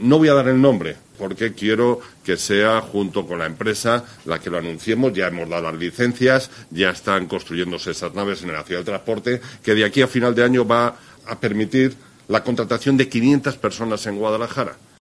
El alcalde de Guadalajara ha hecho este anuncio durante la firma del convenio suscrito entre el Ayuntamiento, la Diputación y CEOE-Cepyme Guadalajara que posibilitará el desarrollo del proyecto "Guadalajara empresarial".